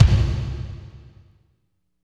36.10 KICK.wav